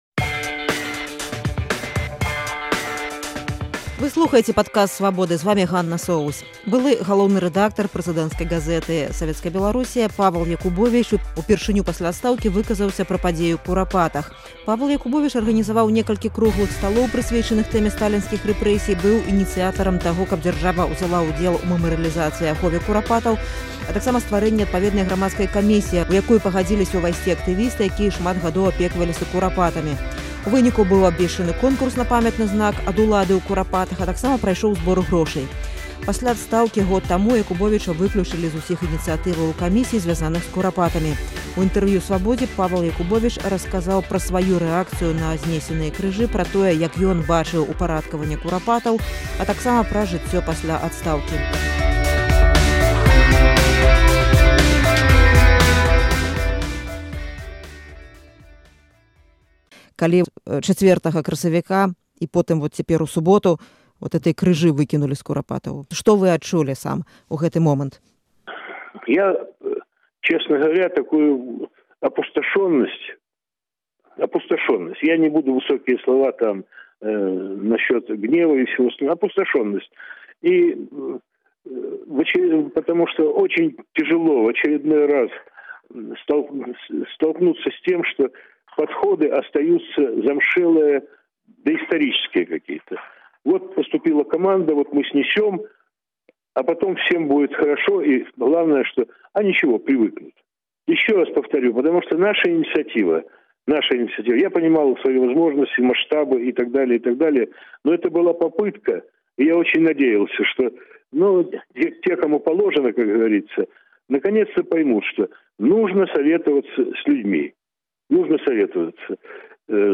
Былы галоўны рэдактар прэзыдэнцкай газэты «СБ. Беларусь сегодня» у інтэрвію Свабодзе расказаў пра сваю рэакцыю на зьнесеныя крыжы, пра тое, як ён бачыў упарадкаваньне Курапатаў, а таксама пра жыцьцё пасьля адстаўкі.